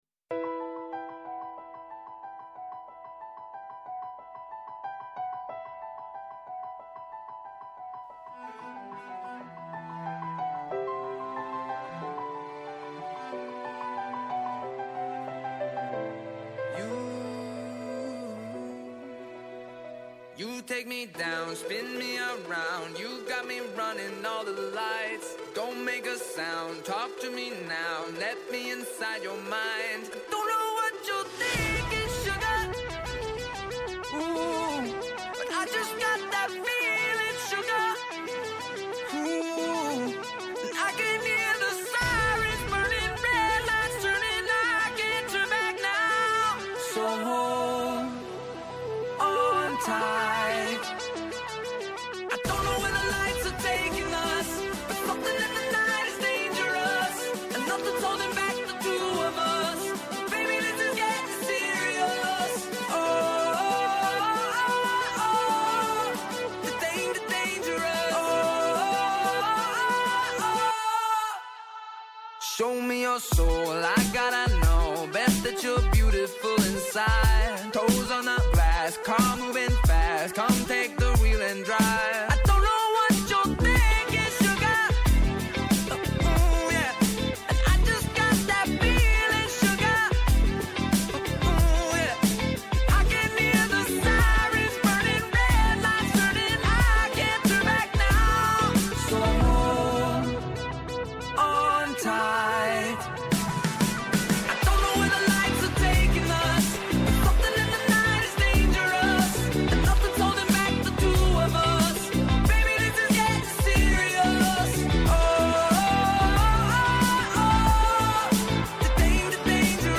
– Σύνδεση με έκτακτη συνέντευξη τύπου του Νίκου Ανδρουλάκη πρ. ΠΑΣΟΚ- ΚΙΝΑΛ για την απόφαση για τις υποκλοπές